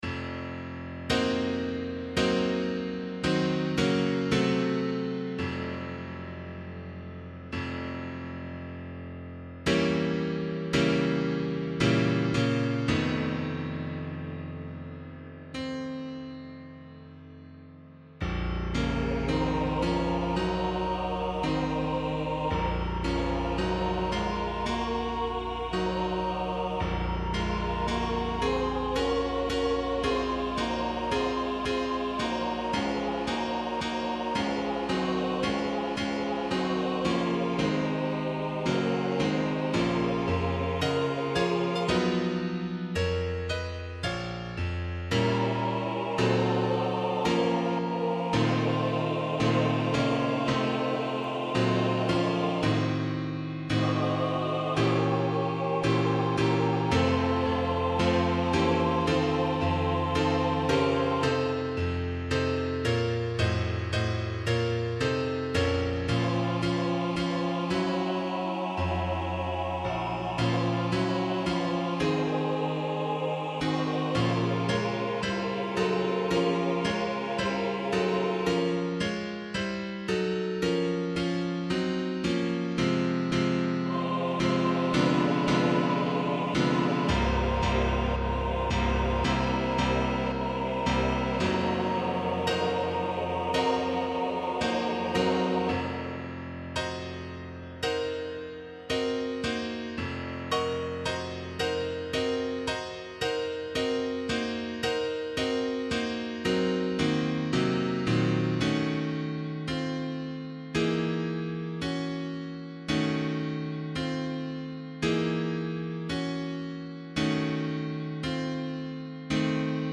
This is an Easter Anthem that I have arranged for SATB choir and piano from a song by David M. Guion with words from Ancient Irish Poetry.
I've included a 2 page voice parts score and a computer generated sound file.
Voicing/Instrumentation: SATB